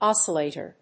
音節ós・cil・là・tor 発音記号・読み方
/‐ṭɚ(米国英語), ‐tə(英国英語)/
音節os･cil･la･tor発音記号・読み方ɑ́səlèɪtər|ɔ́-
oscillator.mp3